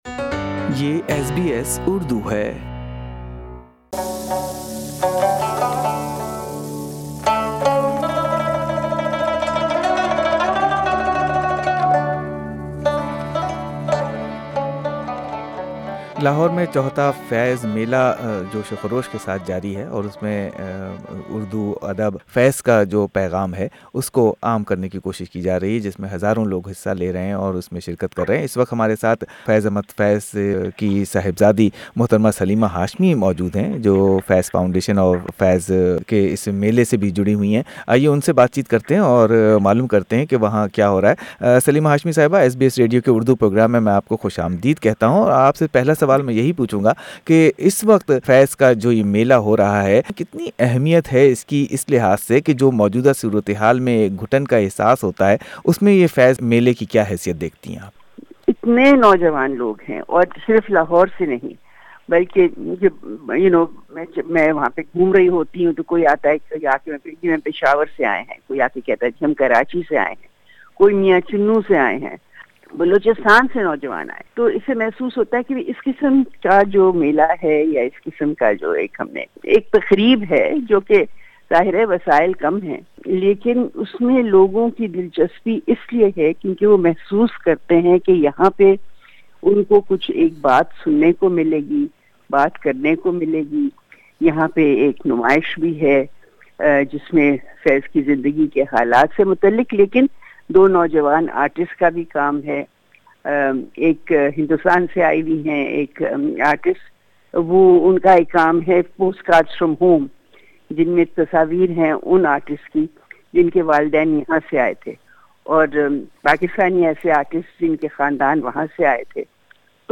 Why Faiz still fascinates - Faiz's daughter Salima Hashmi talks about legendary Poet and Faiz festival